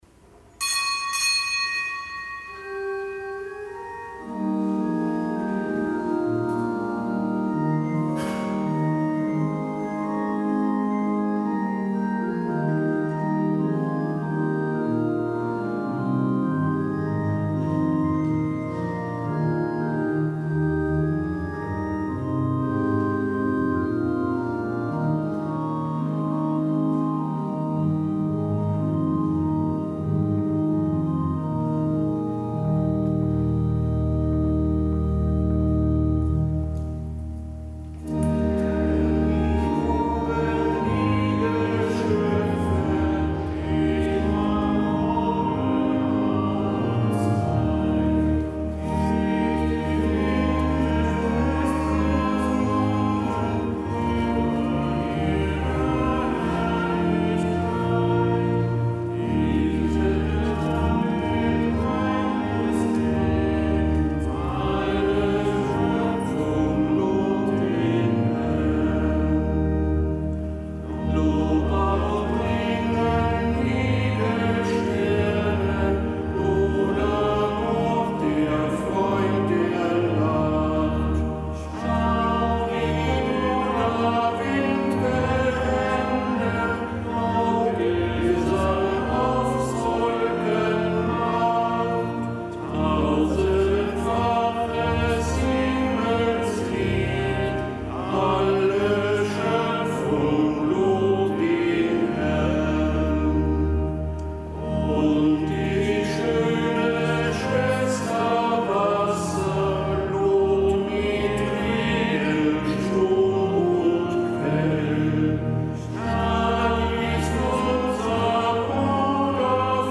Kapitelsmesse am Gedenktag des Heiligen Franz von Assisi
Kapitelsmesse aus dem Kölner Dom am Gedenktag des Heiligen Franz von Assisi, einem Ordensgründer.